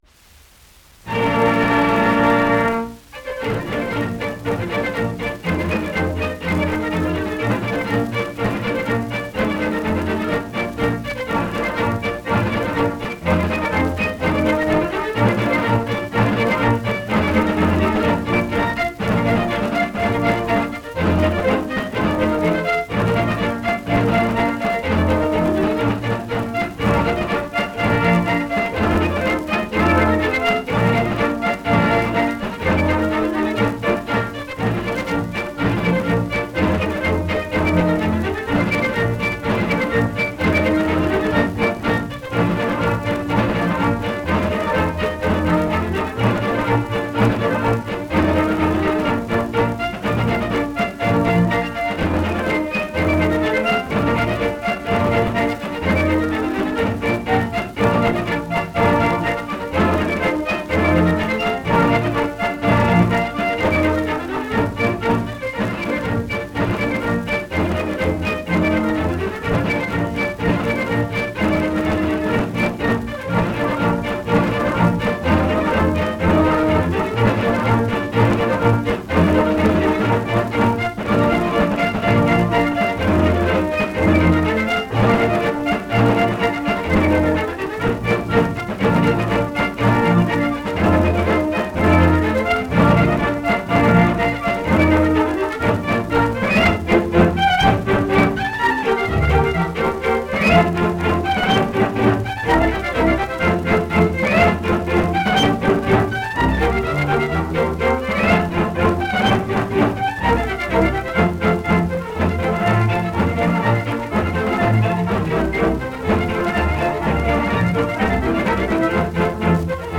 1 10 inch 78rpm shellac disc